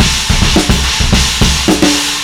China Rock Slammer 01.WAV